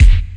bass drum 4.wav